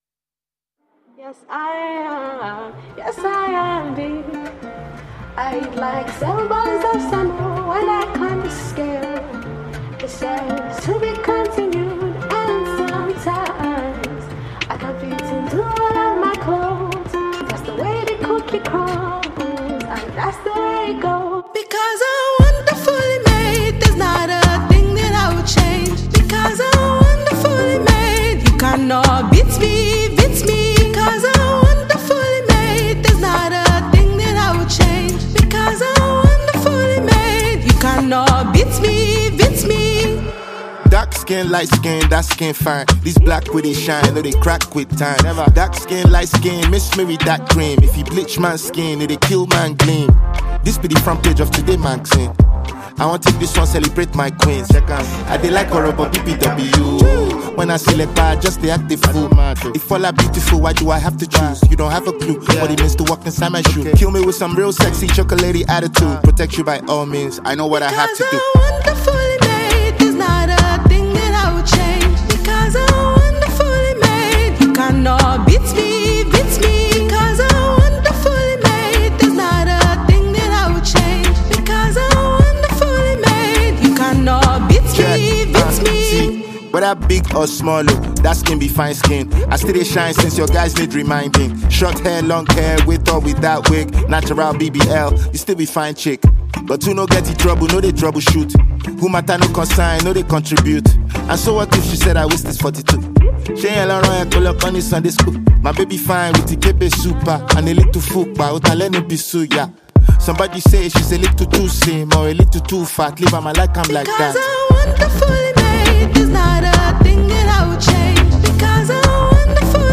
Nigerian rapper and singer
offering a mix of Afrobeat, hip-hop, and highlife sounds.